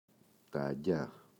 αγγειά, τα [a’ŋɟa]